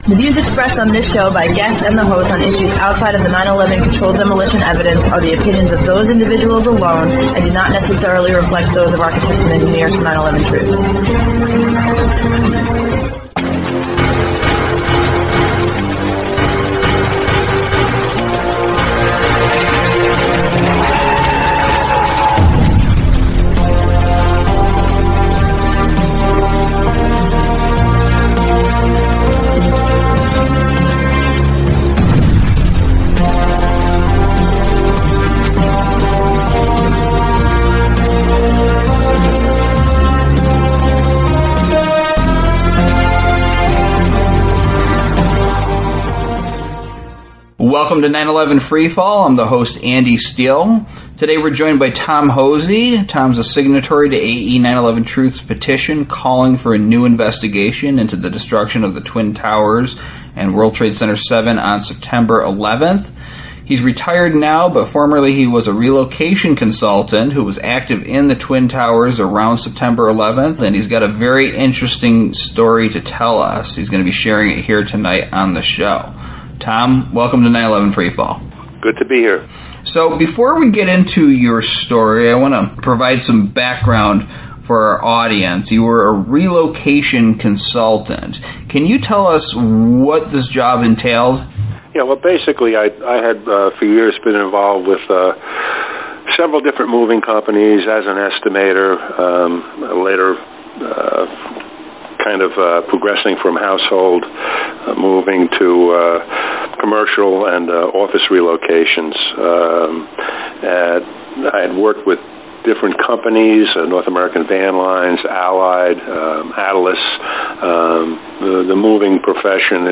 Talk Show
Each episode, we interview one of the many researchers and activists who are blowing the lid off the crime of the century.